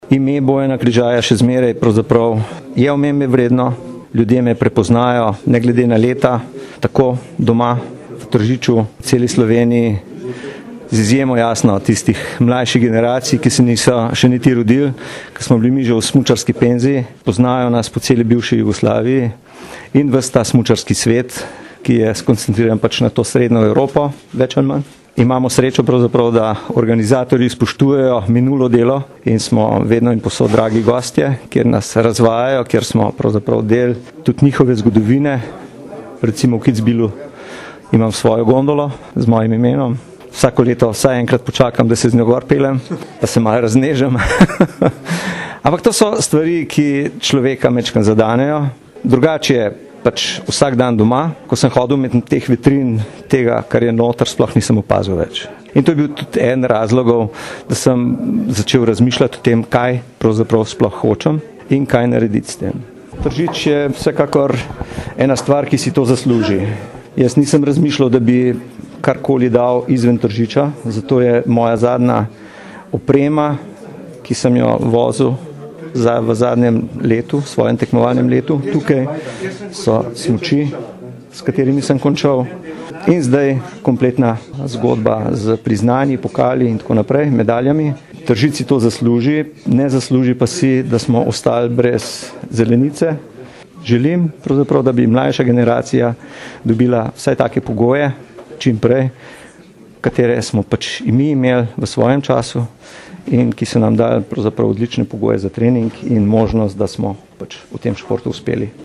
izjava_bojankrizaj.mp3 (2,6MB)